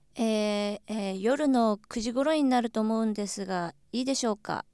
実際の話し言葉に見られる言い淀み・言い直し・語尾の変化などを含む自然発話を、極めて静かな環境にて高音質で収録しています。
発話タスク 旅行に関する模擬会話形式による対話想定発話（非対面収録）
48k音声データ 　：WAV形式(48kHz,16bit,MONO)
サンプル2(女性)